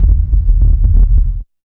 80 RUMBLE -L.wav